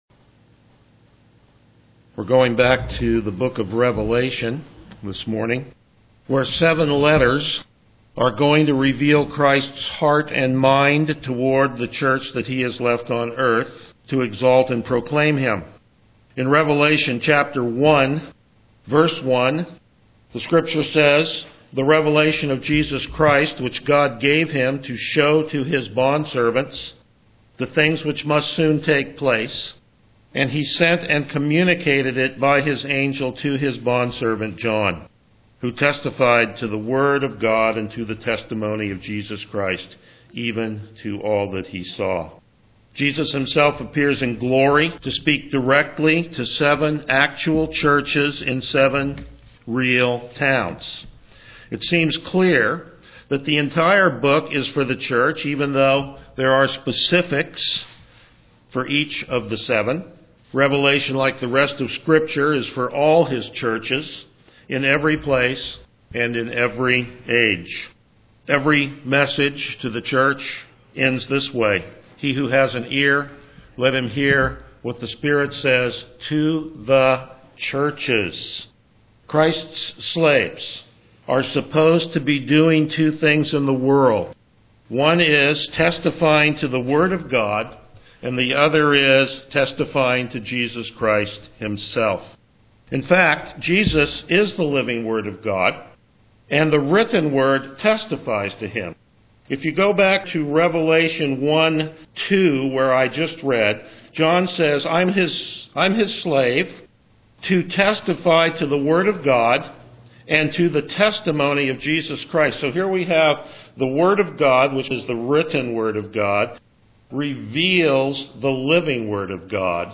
Passage: Revelation 2:1-7 Service Type: Morning Worship